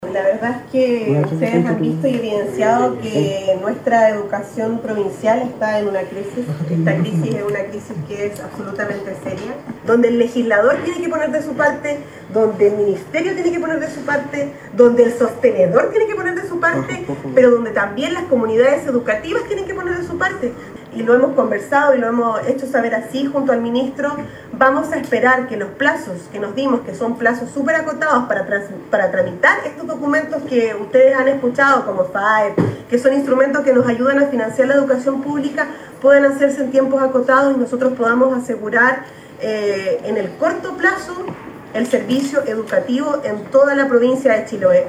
La alcaldesa de Curaco de Vélez Javiera Yáñez invitó a todas las partes involucradas en la búsqueda de soluciones a colocar todo el empeño necesario para alcanzar los objetivos, apuntando a los parlamentarios de la zona principalmente.